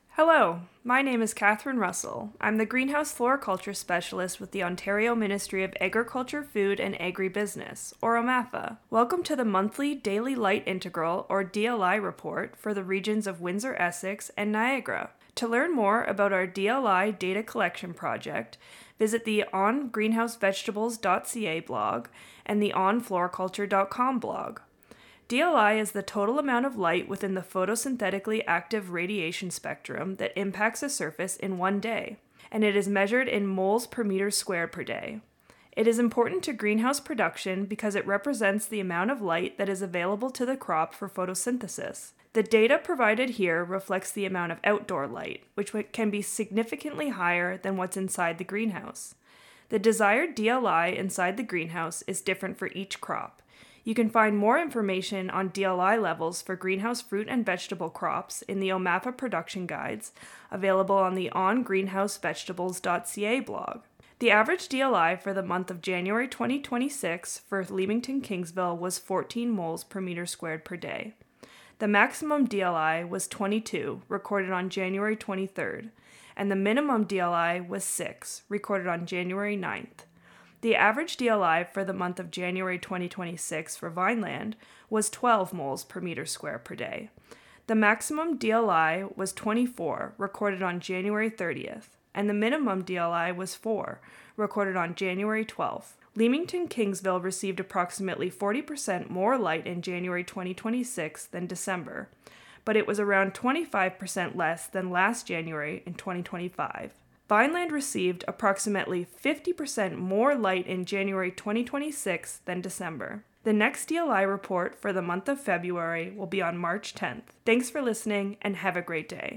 We’re excited to share a new monthly feature for this project: a 2-minute DLI update for the Leamington-Kingsville and Vineland regions! Tune in on the second Tuesday of each month at 12:00 pm during the Noon Farm Show on CFCO Country 92.9, covering Windsor to Woodstock.
Radio recording for January, 2026: